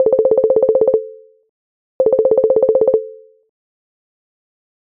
Calls 🤙